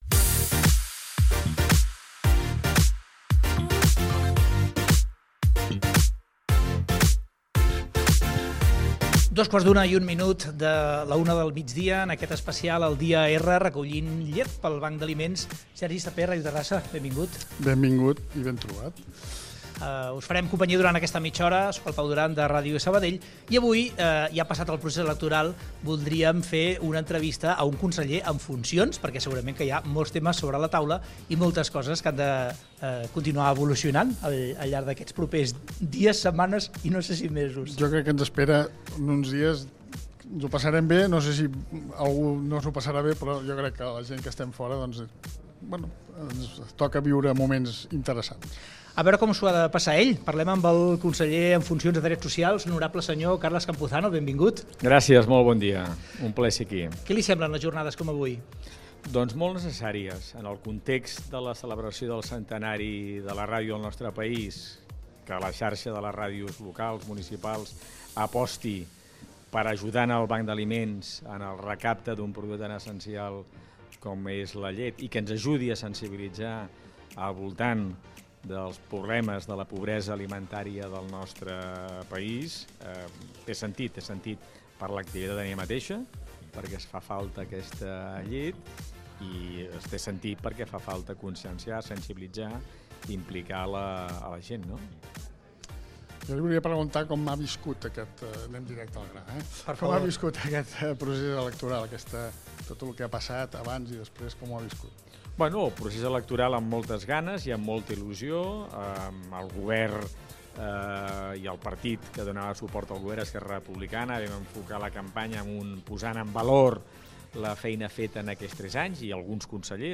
Fragment d'una entrevista al conseller de drets socials en funcions, Carles Campuzano. Veus de Ràdio Sabadell (municipal) i Terrassa FM.